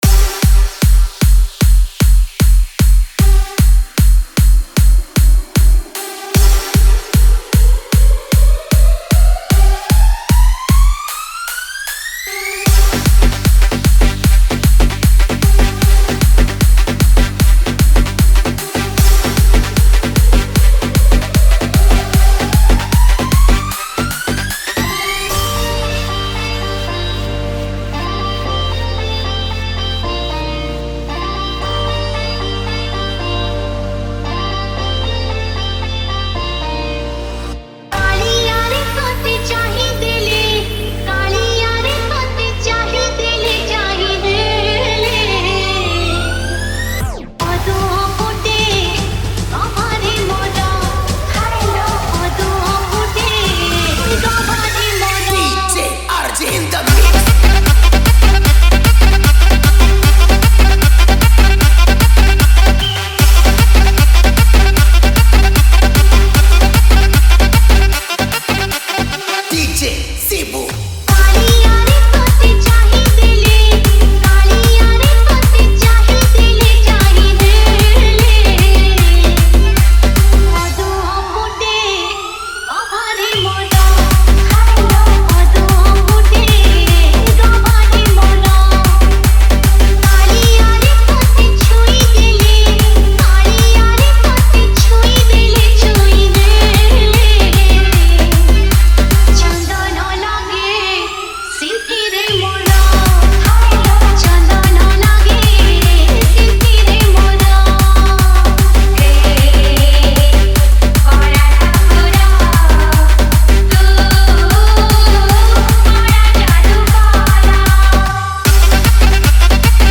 Category:  Odia Bhajan Dj 2024